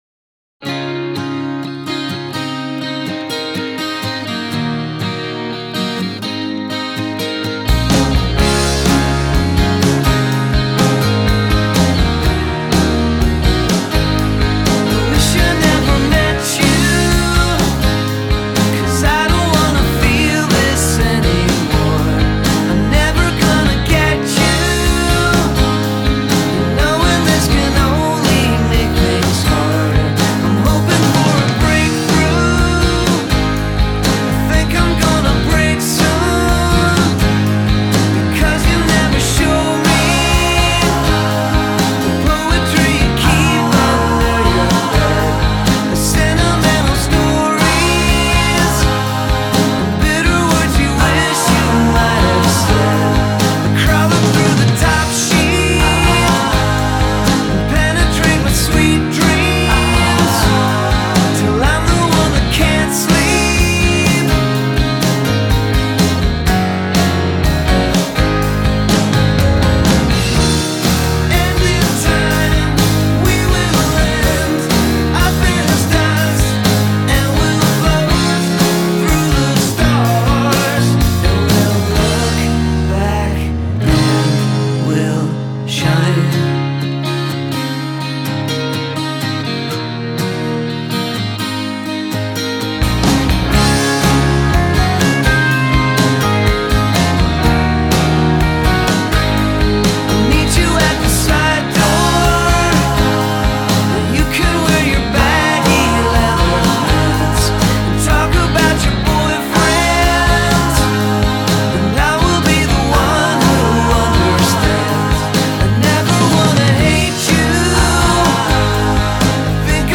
bittersweet